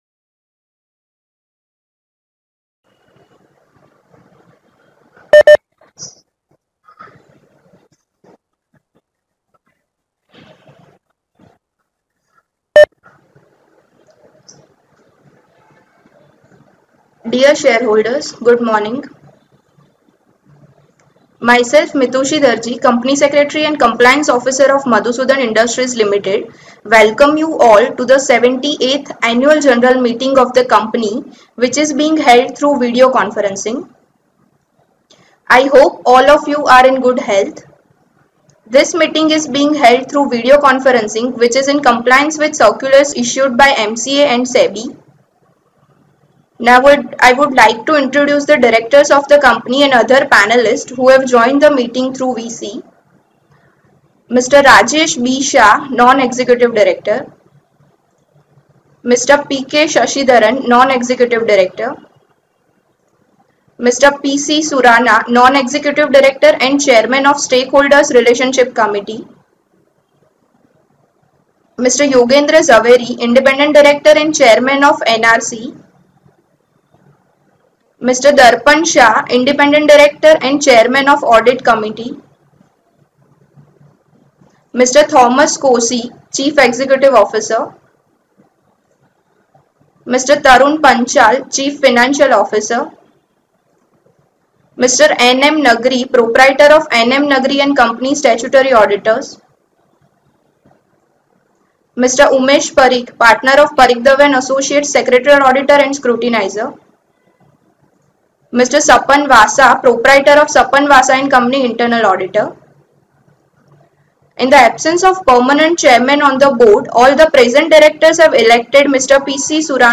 Audio Recording of 78th AGM
Audio_Recording_of_78th_AGM.mp3